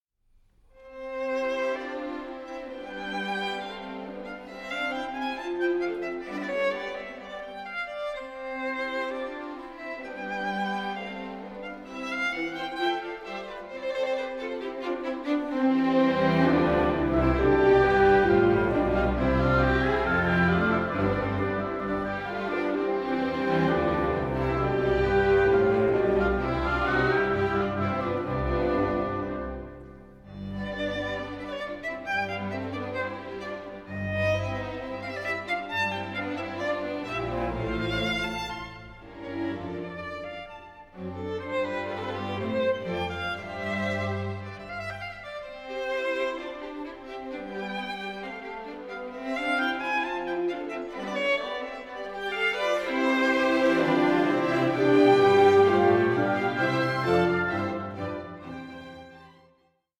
Presto 6:24